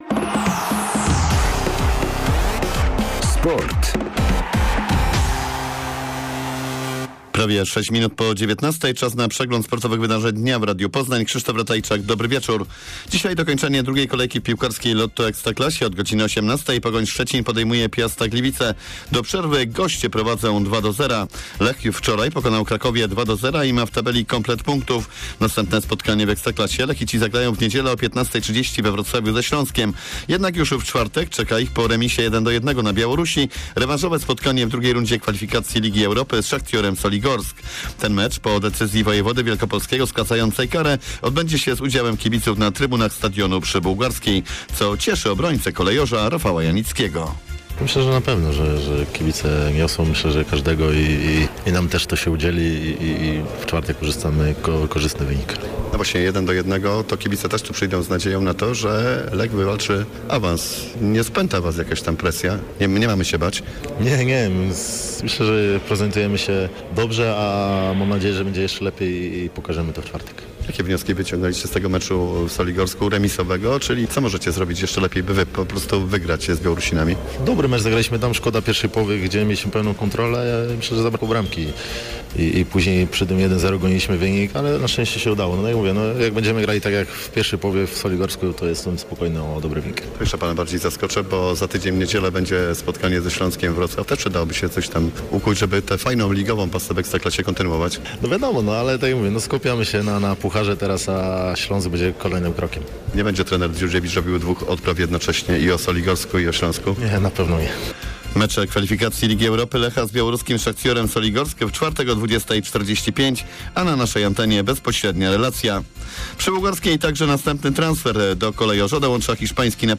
30.07 serwis sportowy godz. 19:05